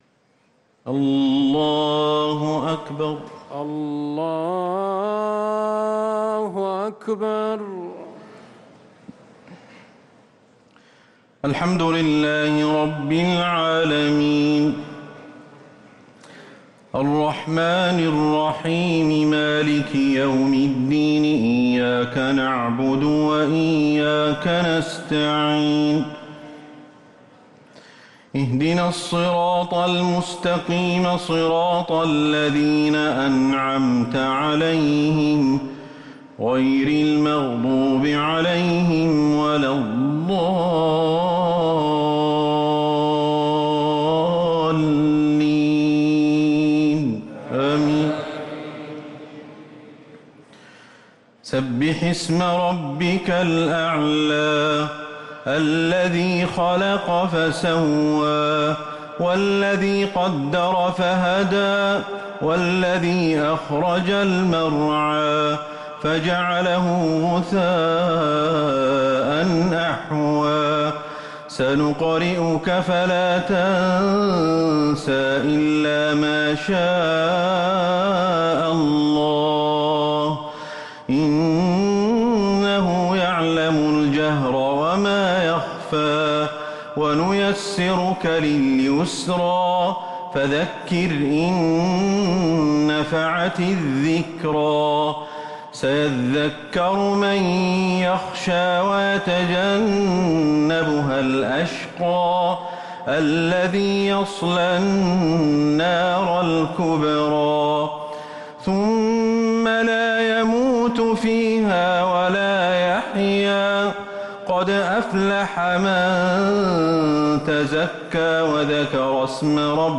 صلاة التراويح ليلة 21 رمضان 1444 للقارئ أحمد الحذيفي - الشفع والوتر - صلاة التراويح